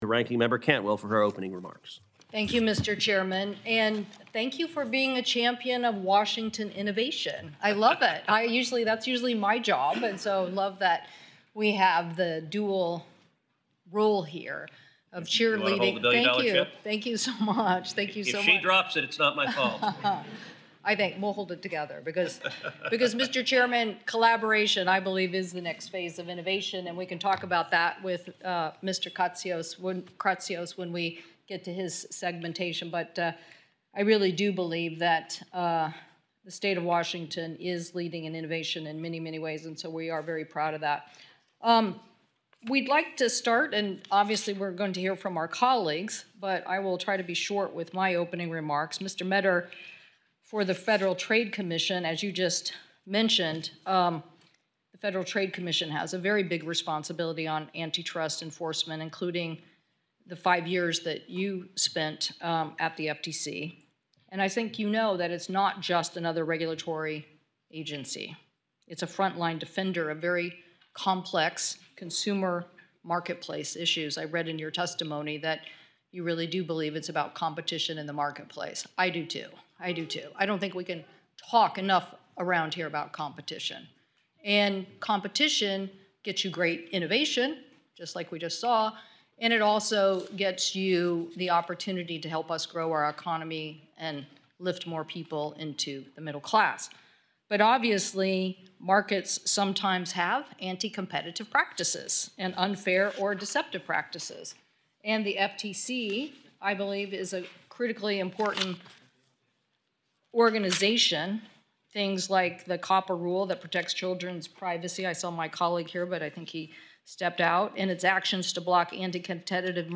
Ranking Member Cantwell Delivers Opening Statement at Nomination Hearing for Michael Kratsios to Lead OSTP & Mark Meador as FTC Commissioner
WASHINGTON, D.C. – U.S. Senator Maria Cantwell, Ranking Member of the Senate Committee on Commerce, Science and Transportation delivered the following opening statement at today’s nomination hearing for Mark Meador to serve as a Federal Trade Commissioner Michael Kratsios to lead the Office of Science and Technology.